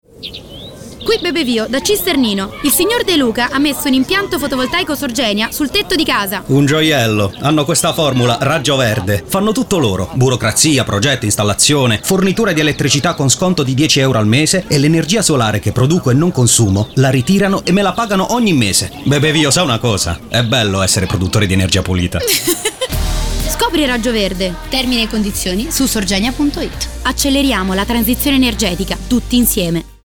Sorgenia: on air la campagna radio dedicata al FV residenziale
Protagonista della campagna radio, che sarà trasmessa sulle principali emittenti nazionali, è Bebe Vio.
Ogni spot della durata di 30 secondi si conclude con la voce di Bebe Vio e lo slogan “Acceleriamo la transizione energetica, tutti insieme”.